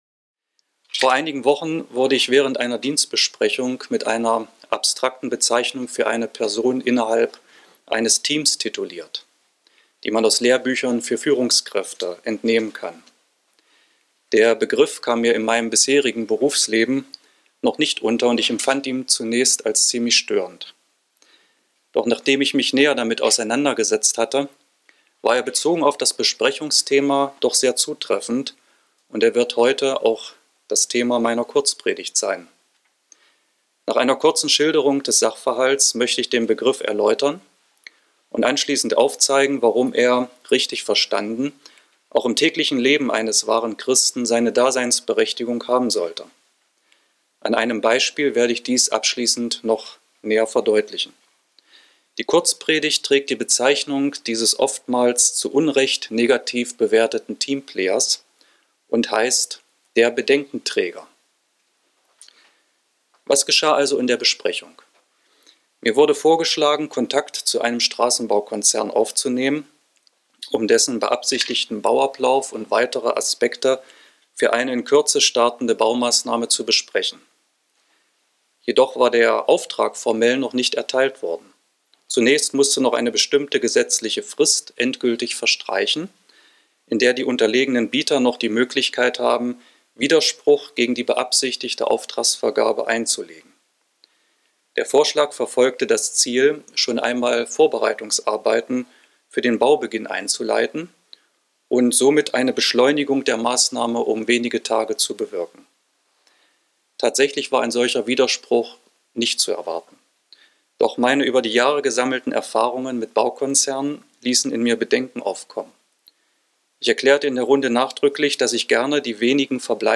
Sollte ein Christ in dieser Welt generell ein solcher Bedenkenträger sein? Die Kurzpredigt wird die Frage ausführlich beantworten und hierbei auch auf ein aktuelles Beispiel eingehen, welches die Zeit vor Weihnachten zum Gegenstand hat.